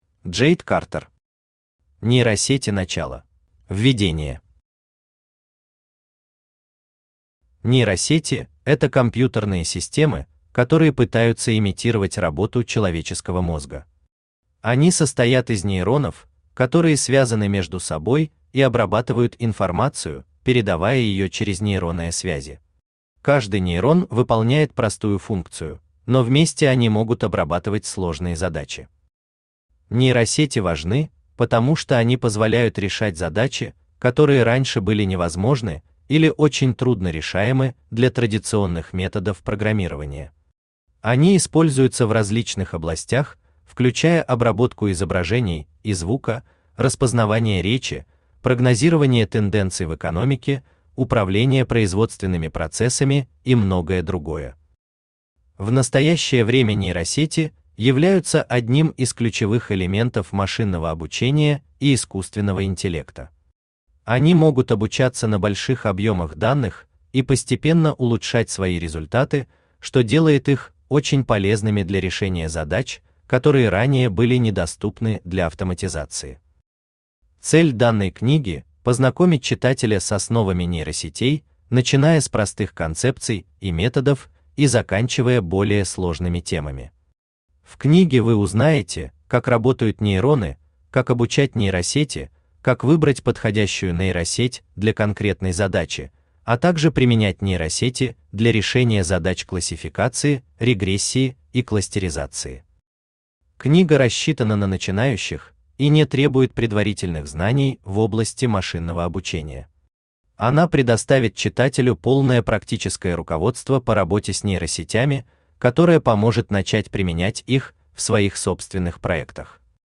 Аудиокнига Нейросети начало | Библиотека аудиокниг
Aудиокнига Нейросети начало Автор Джейд Картер Читает аудиокнигу Авточтец ЛитРес.